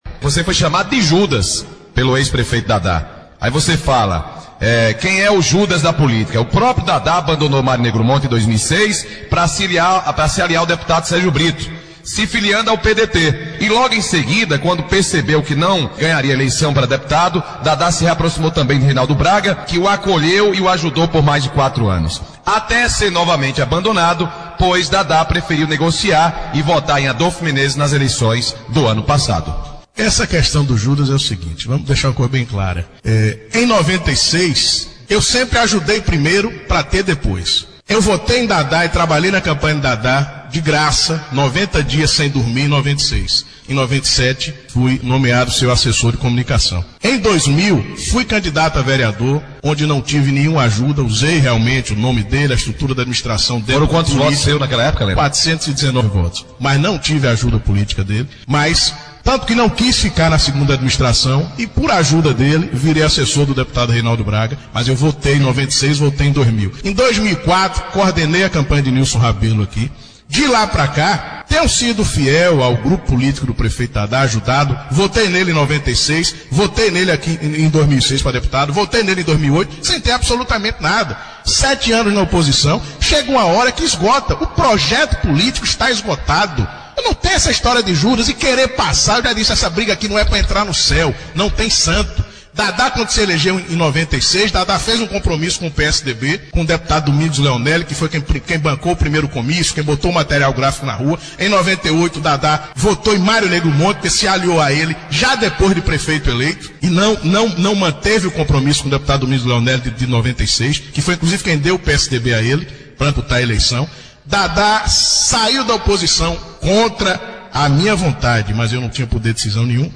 entrevista
no programa Primeira Página, da Rádio Povo AM – Ribeira do Pombal – BA.